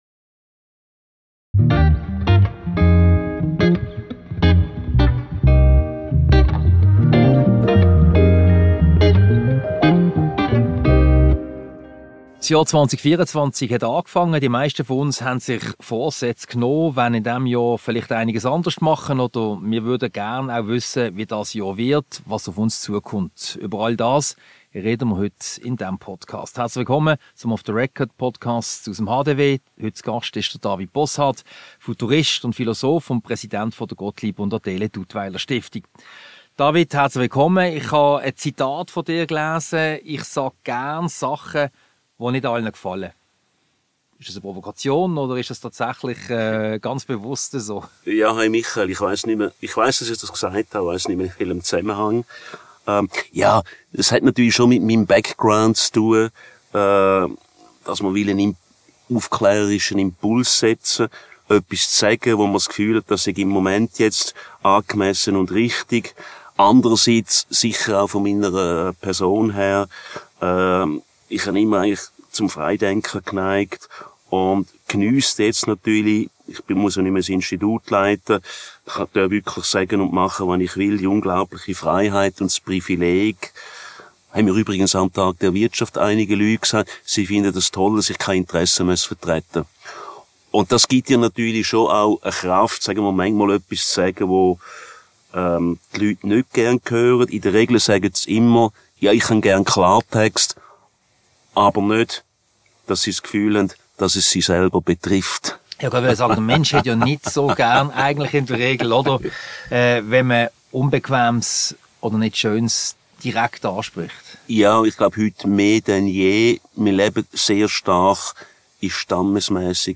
Ein Gespräch über Wünsche, Vorsätze und wie das Jahr 2024 wird.